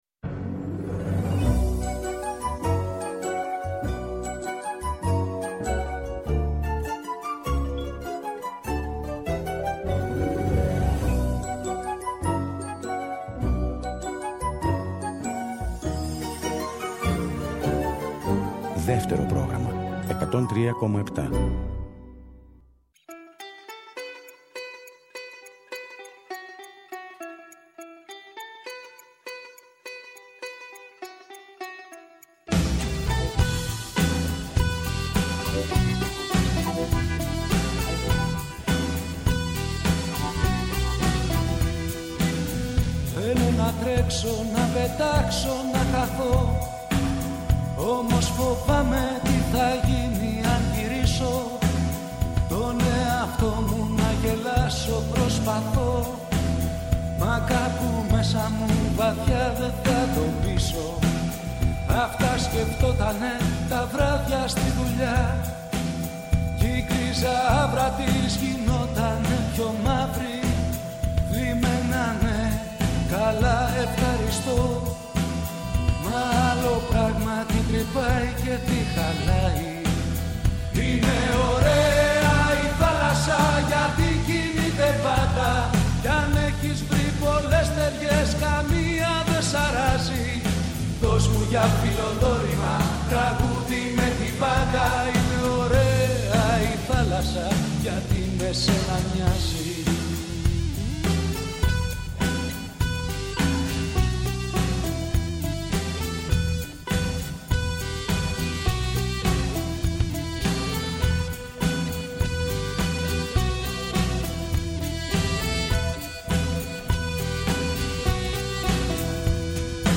Πολυσυλλεκτική ραδιοφωνική περιπλάνηση με τραγούδια και γεγονότα.